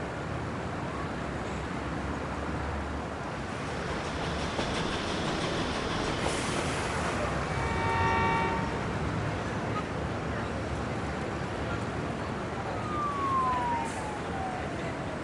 downtown_loop.ogg